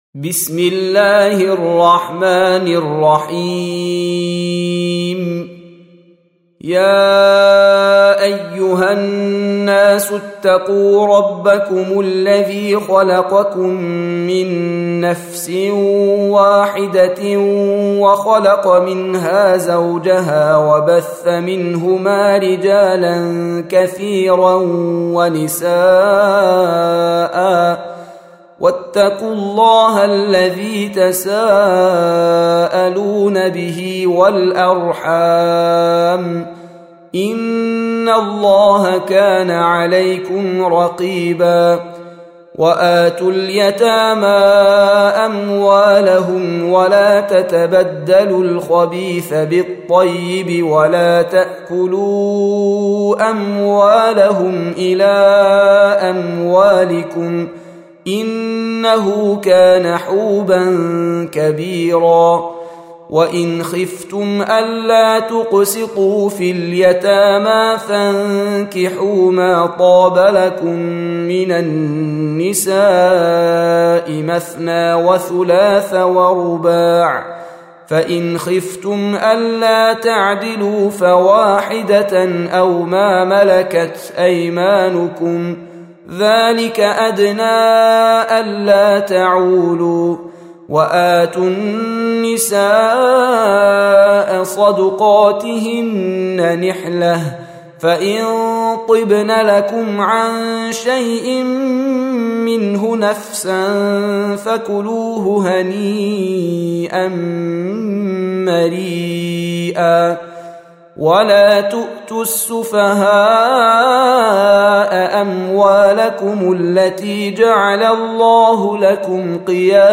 Audio Quran Tarteel Recitation
Surah Repeating تكرار السورة Download Surah حمّل السورة Reciting Murattalah Audio for 4. Surah An-Nis�' سورة النساء N.B *Surah Includes Al-Basmalah Reciters Sequents تتابع التلاوات Reciters Repeats تكرار التلاوات